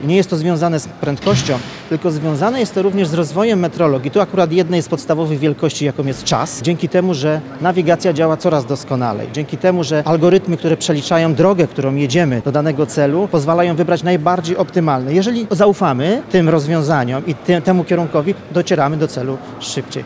O doskonaleniu technik pomiarowych rozmawiają eksperci w Lublinie podczas Kongresu Gospodarczego „Metrologia Przyszłości”.